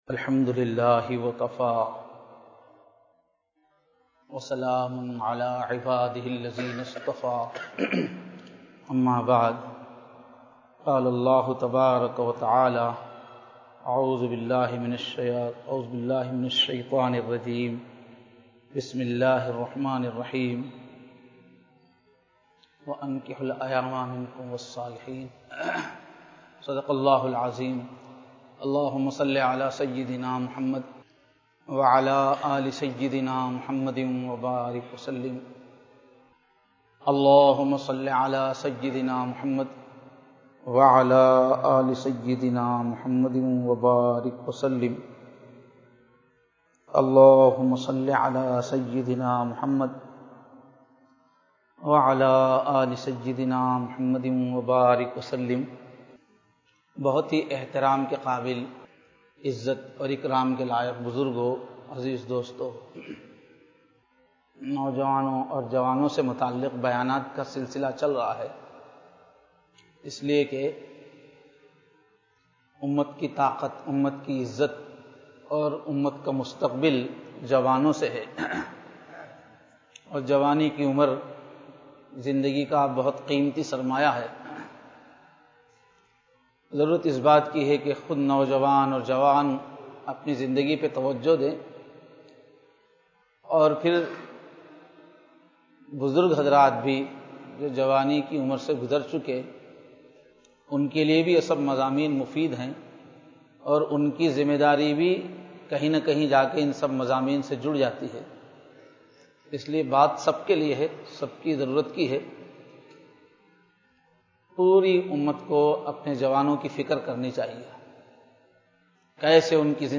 Bayanath
Jummah